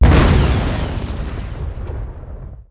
grenade_explode.wav